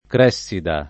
Cressida [ kr $SS ida ; ingl. kr $S idë ]